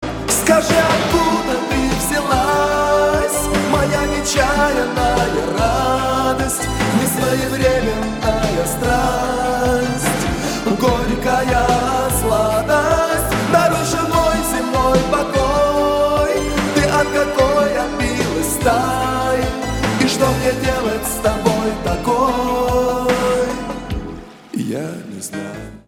• Качество: 320, Stereo
поп
красивый мужской голос
спокойные